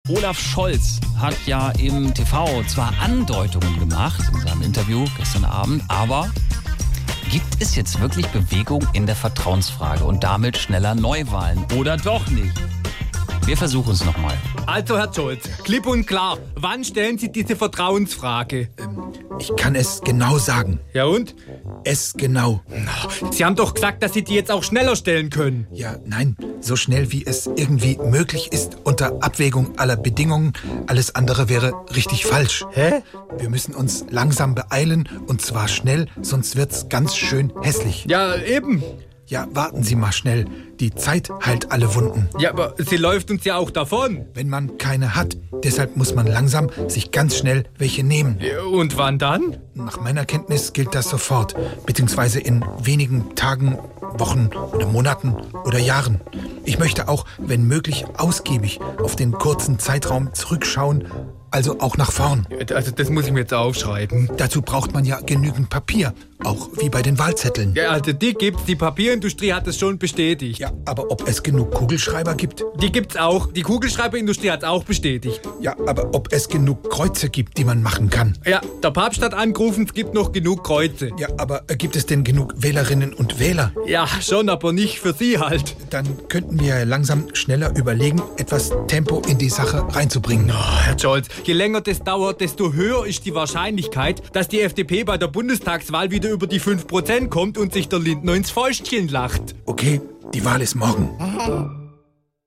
SWR3 Comedy Olaf Scholz klipp und klar